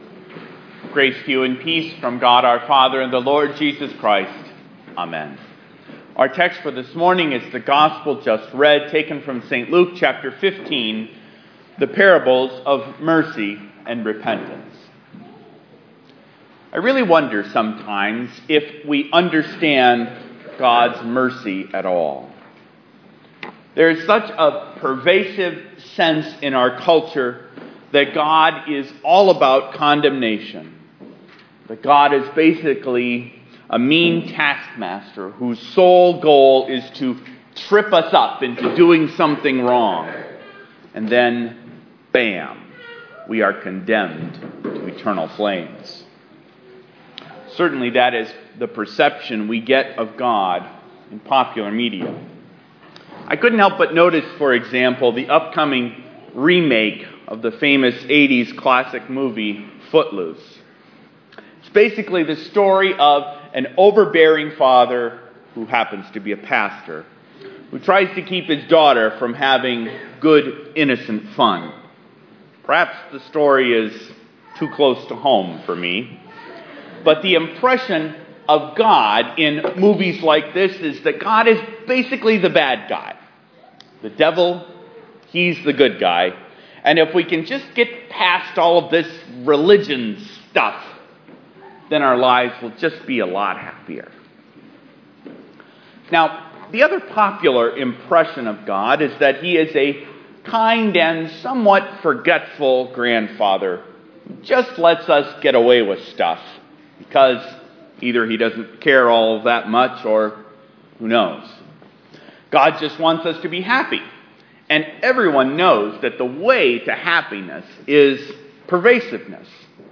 Messiah Lutheran Church Kenosha, Wisconsin